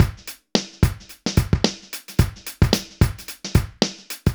Index of /90_sSampleCDs/AKAI S6000 CD-ROM - Volume 4/Others-Loop/BPM110_Others2